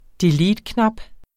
Udtale [ deˈliːd- ]